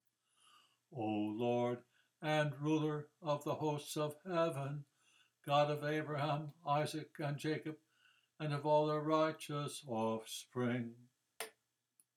Penitence – Easy chants and committed language for the Daily Office
A-Song-of-Penitence-G-Tone-IID-W.m4a